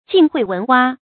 晉惠聞蛙 注音： ㄐㄧㄣˋ ㄏㄨㄟˋ ㄨㄣˊ ㄨㄚ 讀音讀法： 意思解釋： 比喻愚昧寡聞。